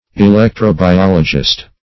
Search Result for " electro-biologist" : The Collaborative International Dictionary of English v.0.48: Electro-biologist \E*lec`tro-bi*ol"o*gist\, n. (Biol.) One versed in electro-biology.